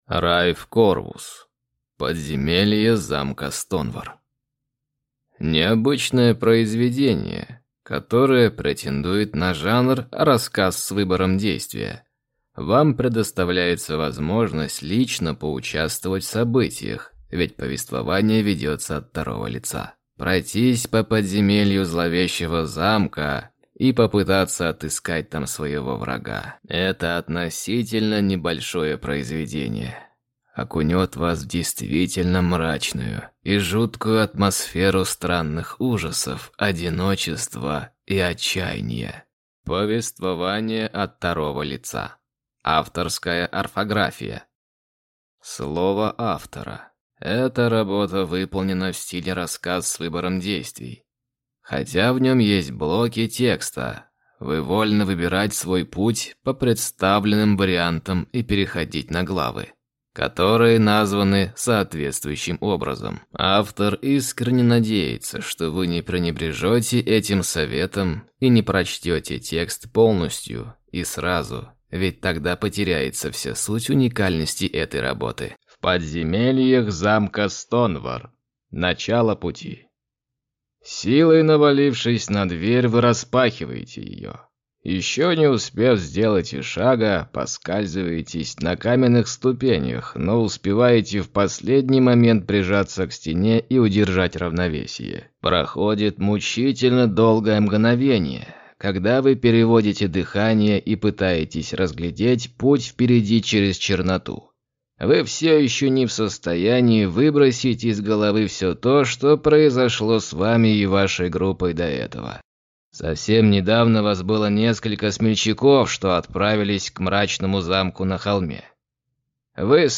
Аудиокнига Подземелье замка Стонвар | Библиотека аудиокниг